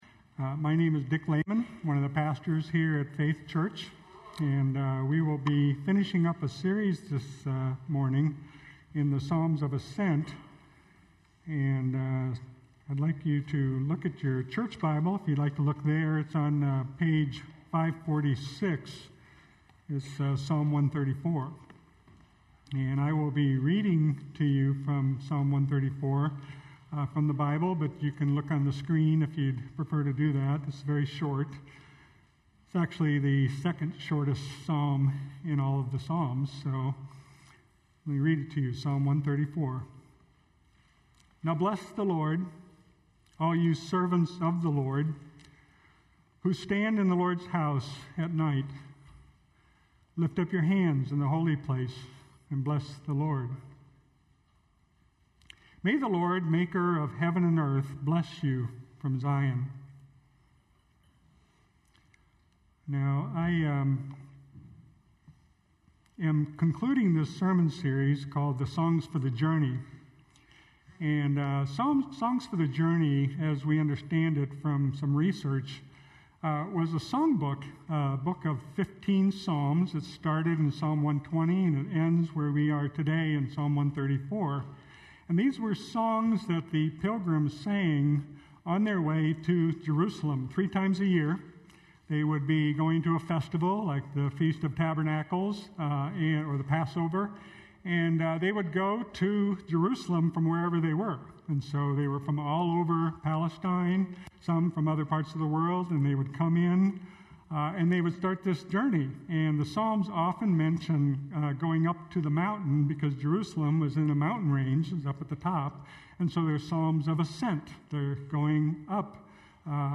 Faith Church Sermon Podcast Songs for the Journey-Psalms of Ascent - Being Blessed Sep 02 2018 | 00:46:07 1x 00:00 / 00:46:07 Subscribe Share Spotify RSS Feed Share Link Embed